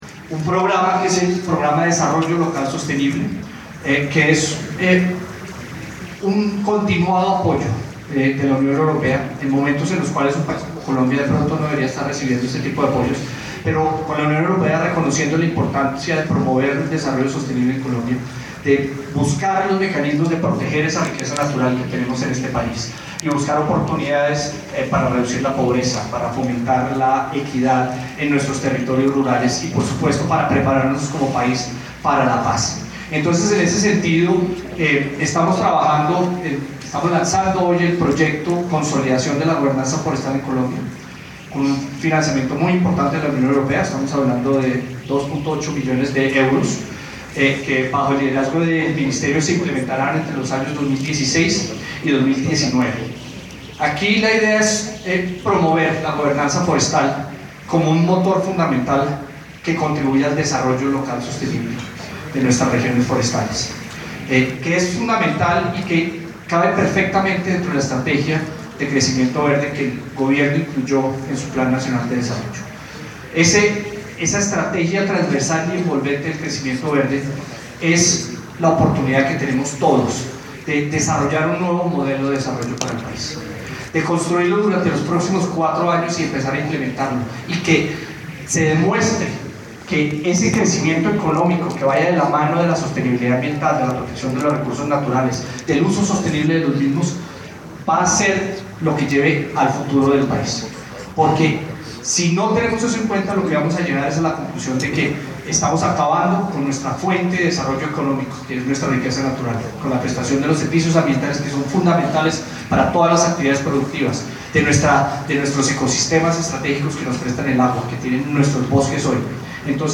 Declaraciones del Viceministro de Ambiente y Desarrollo Sostenible, Pablo Vieira Samper